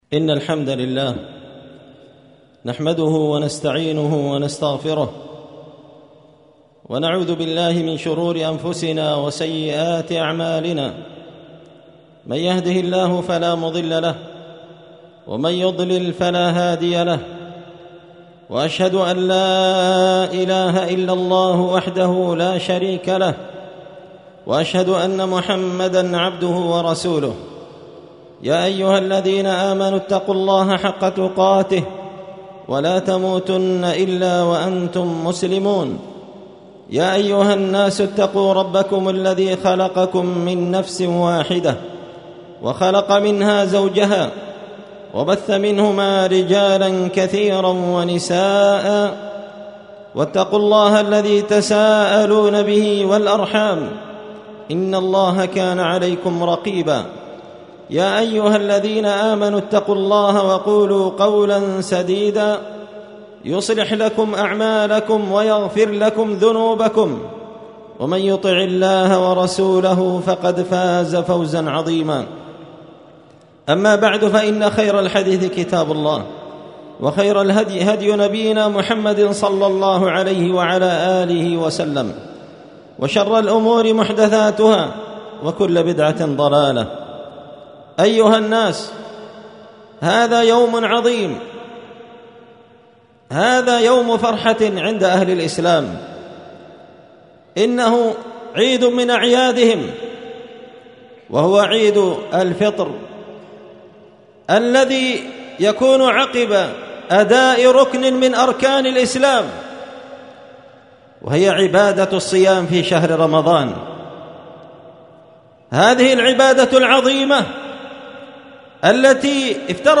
خطبة عيد الفطر المبارك لعام 1445 هجرية
ألقيت هذه الخطبة في مصلى أهل السنة والجماعة قشن-المهرة-اليمن